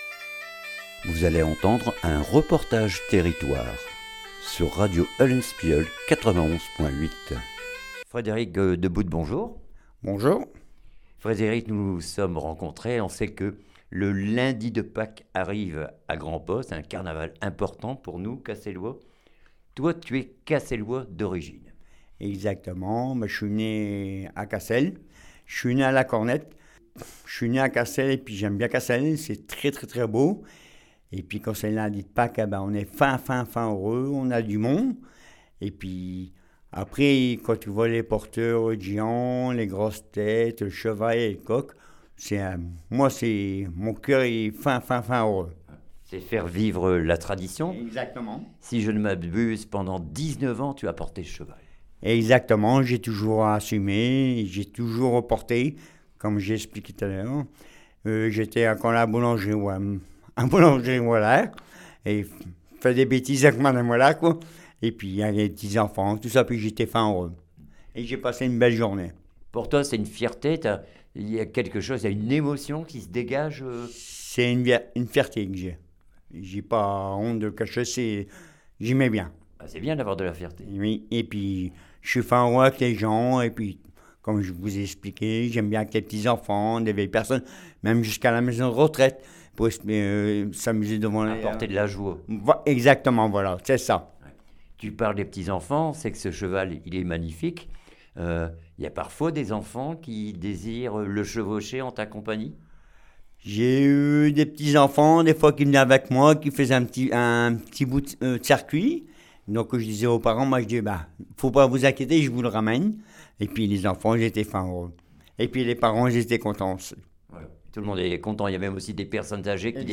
REPORTAGE TERRITOIRE LE CHEVAL JUPON CASSEL